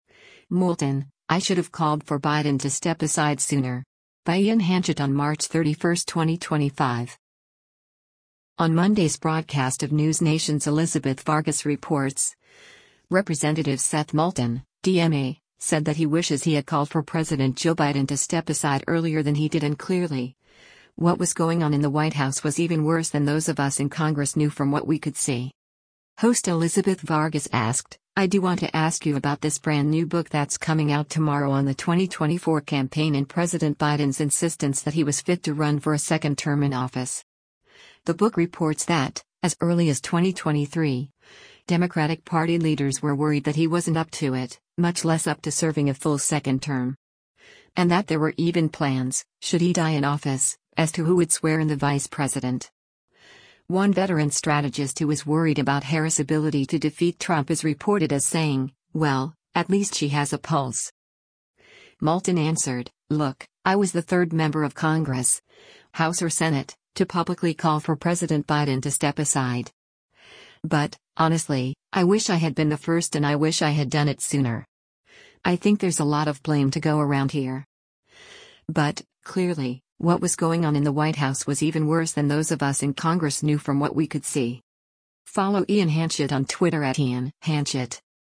On Monday’s broadcast of NewsNation’s “Elizabeth Vargas Reports,” Rep. Seth Moulton (D-MA) said that he wishes he had called for President Joe Biden to step aside earlier than he did and “clearly, what was going on in the White House was even worse than those of us in Congress knew from what we could see.”